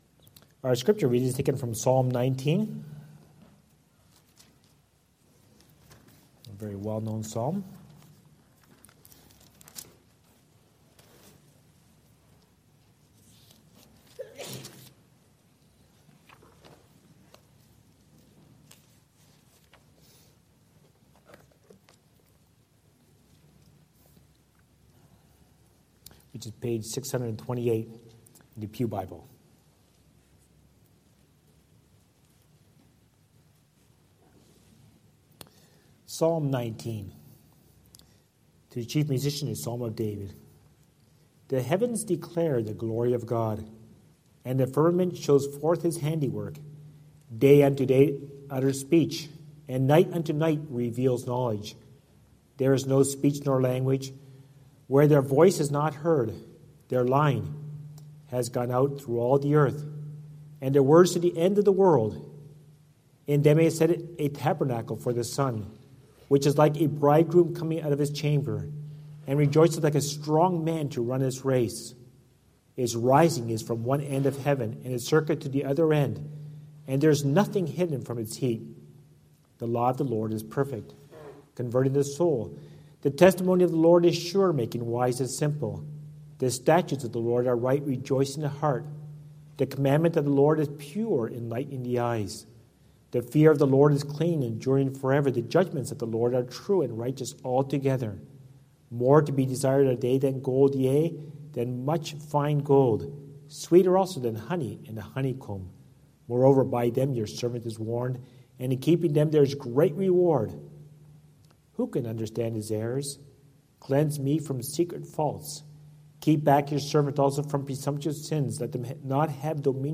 | SermonAudio Broadcaster is Live View the Live Stream Share this sermon Disabled by adblocker Copy URL Copied!
Hope Reformed Church of Brampton